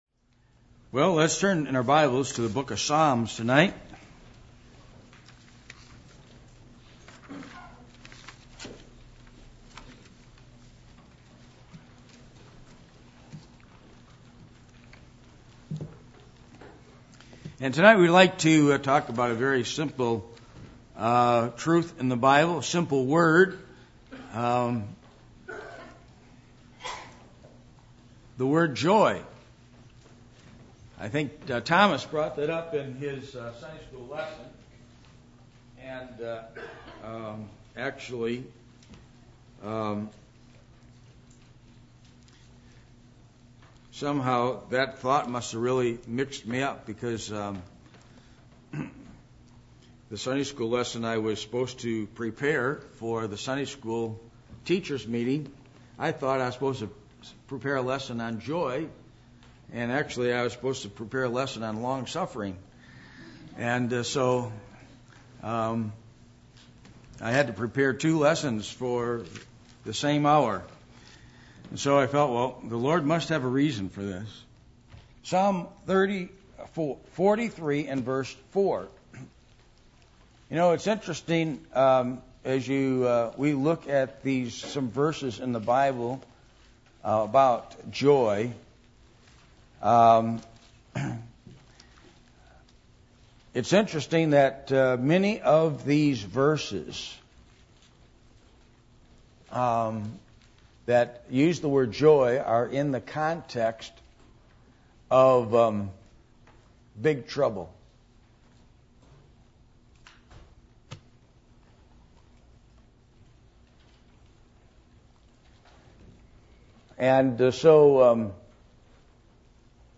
Passage: Psalm 43:1-5, Psalm 32:1-11, Psalm 65:1-13 Service Type: Midweek Meeting